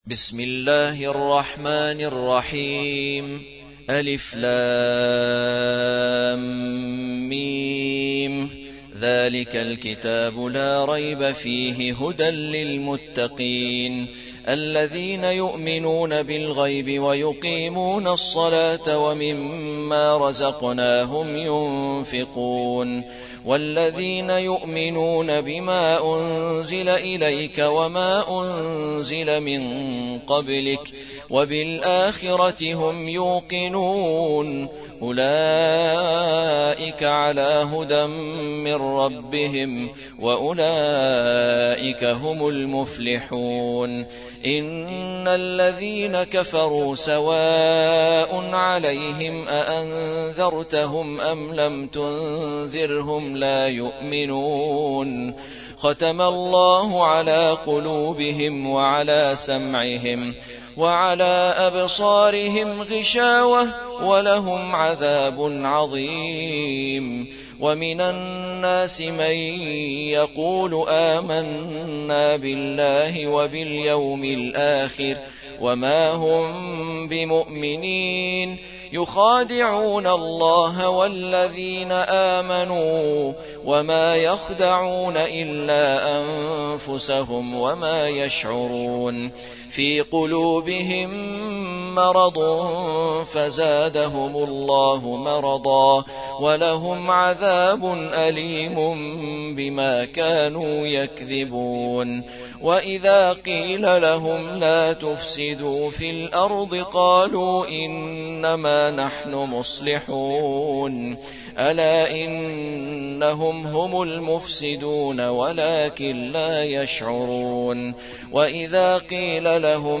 2. سورة البقرة / القارئ